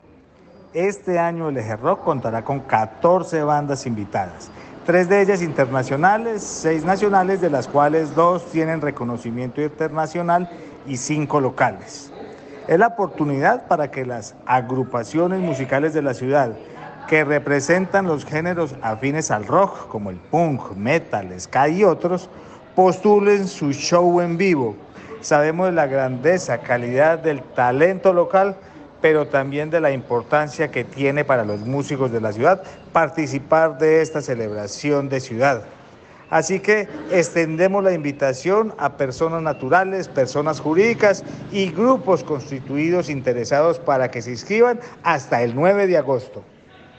Secretario_de_Cultura_Municipal.mp3